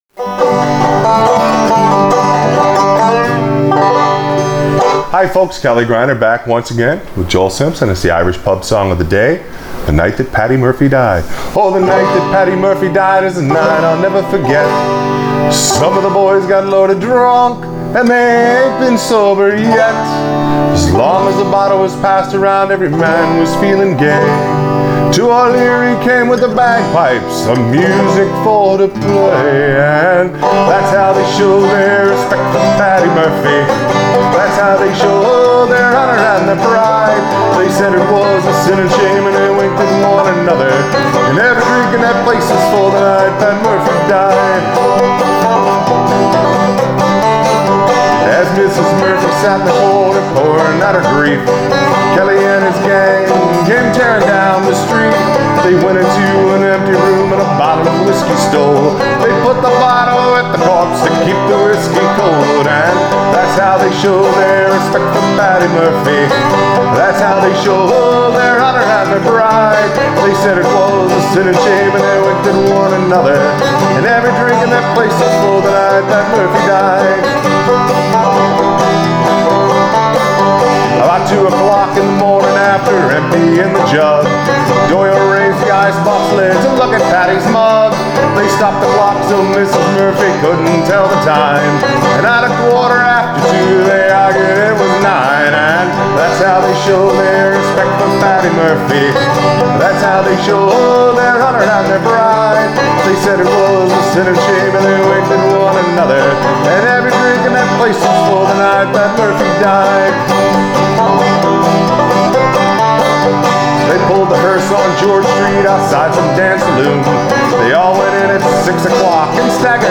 Clawhammer BanjoFrailing BanjoInstructionIrish Pub Song Of The Day